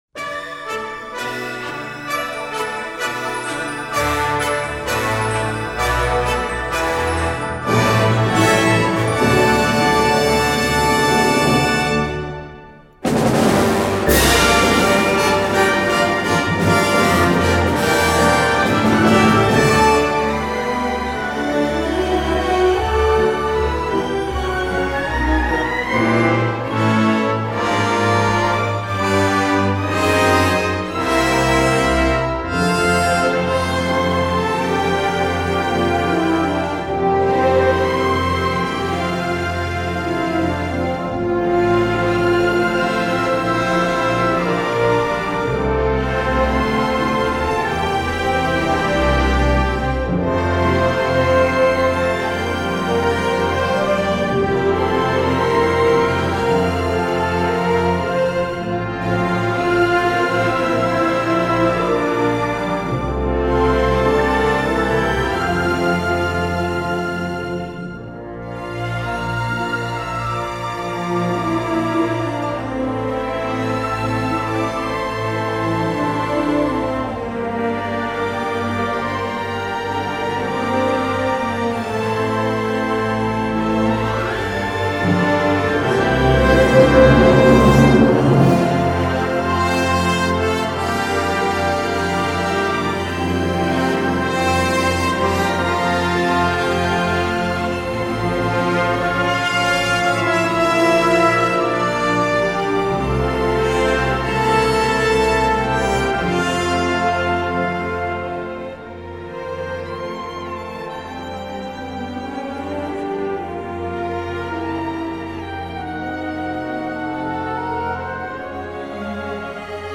原声电影音乐